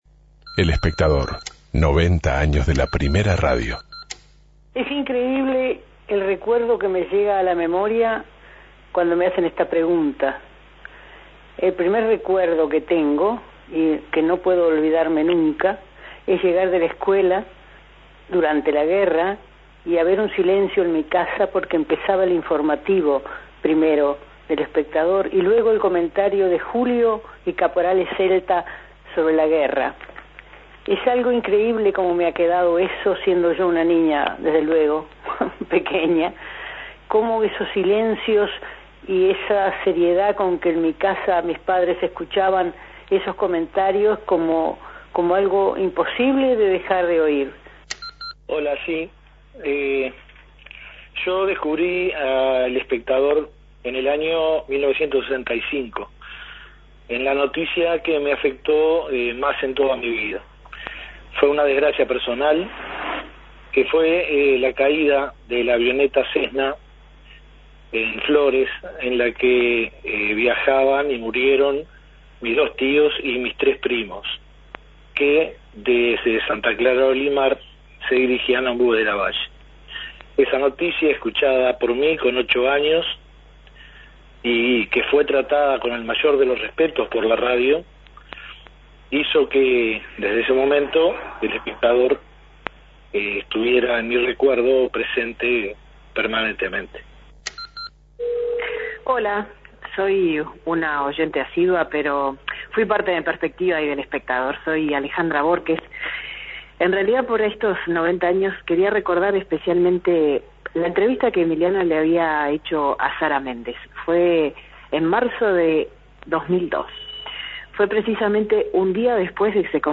Escuchamos mensajes de nuestros oyentes, con sus recuerdos de momentos de los programas de El Espectador que tienen grabados en sus memorias.